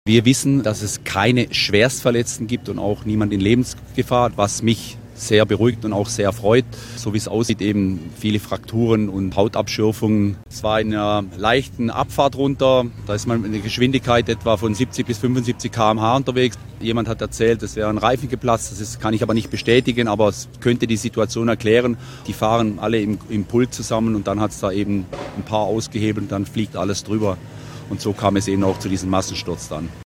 Teilnehmer bei RiderMan